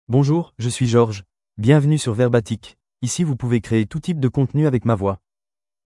GeorgeMale French AI voice
George is a male AI voice for French (France).
Voice sample
Listen to George's male French voice.
George delivers clear pronunciation with authentic France French intonation, making your content sound professionally produced.